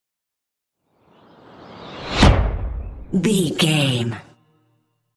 Dramatic whoosh to hit airy trailer
Sound Effects
Atonal
dark
futuristic
intense
tension
woosh to hit